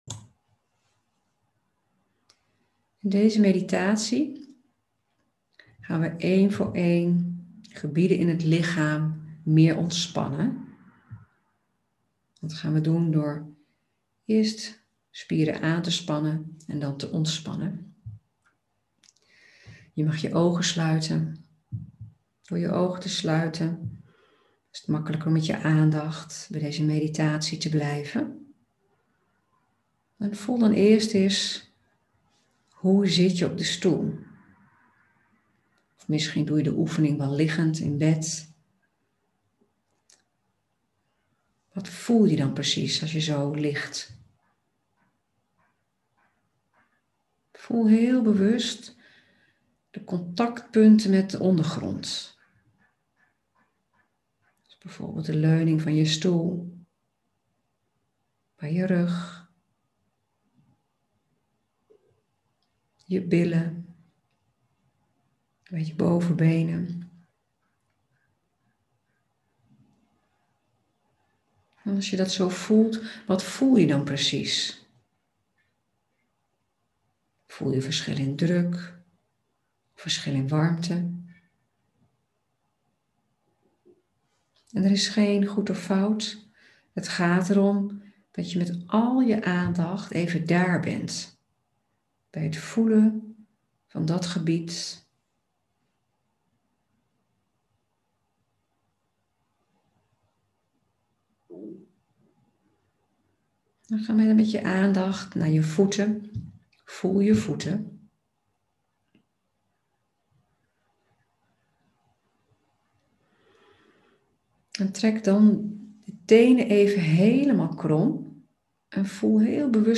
Meditatie-spieren-aanspannen-en-ontspannen.mp3